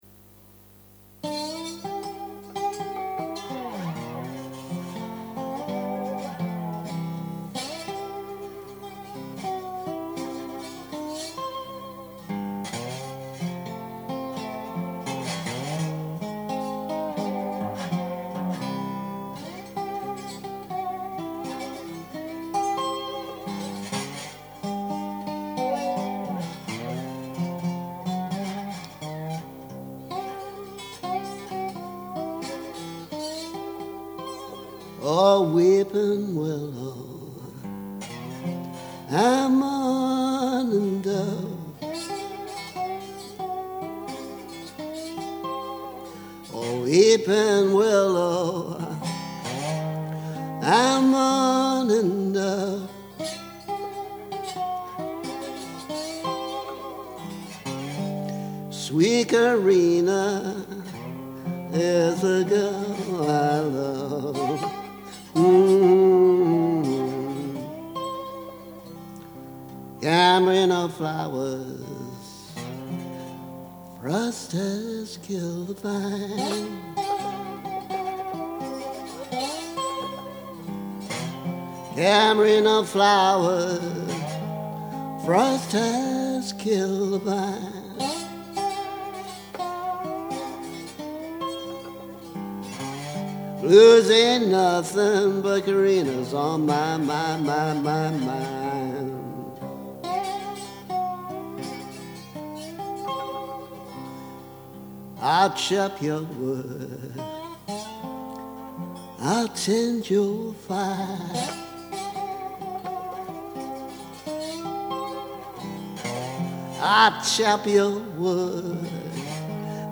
Just to be awkward, I play it slide. :)